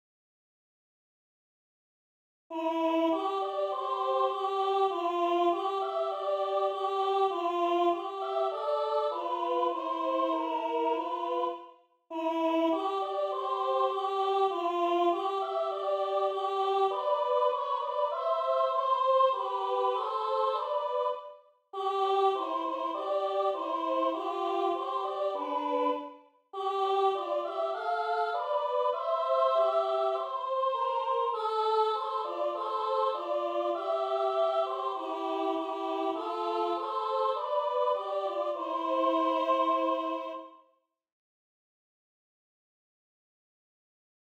America the Beautiful (by Cydney Olson Van Duker -- 2 part choir, SA, Violin Duet/Violin Ensemble Member(s))
This is a SA chor and congregation arrangement. The organ and congregation use the hymnal and the SA sing the arrangement on one or more verses.
Voicing/Instrumentation: SA , 2 part choir , Violin Duet/Violin Ensemble Member(s) We also have other 16 arrangements of " America the Beautiful ".
Includes Vocal Obbligato/Descant